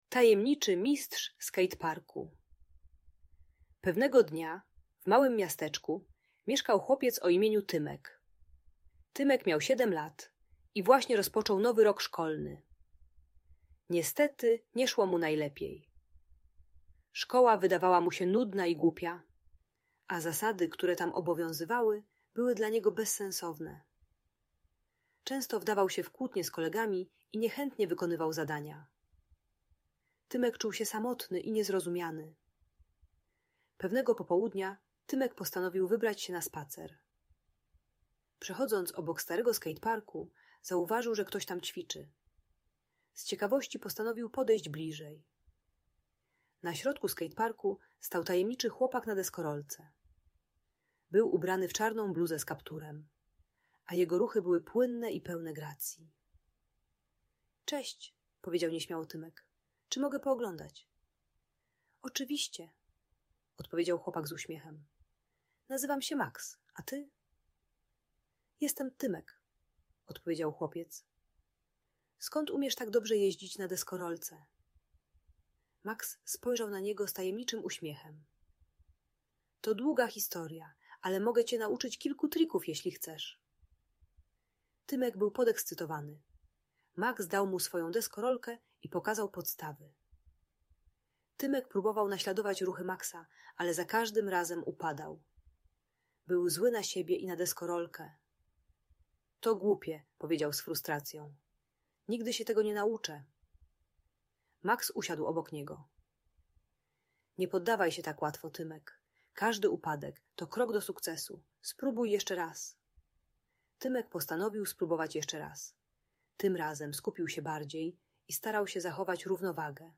Tajemniczy Mistrz Skateparku - Szkoła | Audiobajka